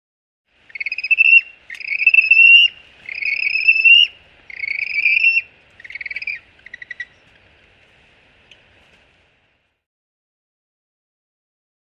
Penguin Chirps. Several Groups Of Warbling Penguin Chirps. Medium Perspective.